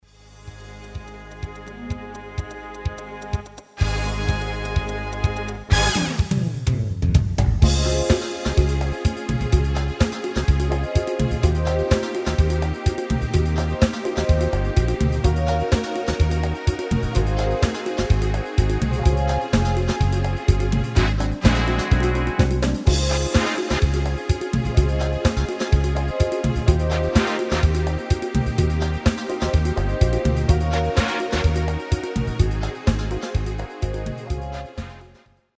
Basic MIDI File Euro 8.50
Demo's zijn eigen opnames van onze digitale arrangementen.